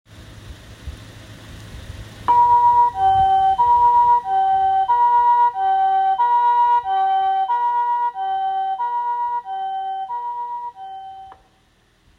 救急出動時
「ピ～・ポ～・ピ～・ポ～」
サイレン音（救急） （mp3） (音声ファイル: 195.9KB)
救急車は同じ「ピ～・ポ～」でも、住宅地を走る際は住民への騒音に配慮して少し低めの「ピ～・ポ～・ピ～・ポ～」を鳴らしたり、「ハーモニックサイレン」といって音を重ねてとがったサイレン音を柔らかくして走行することがあります。